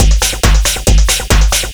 DS 138-BPM B1.wav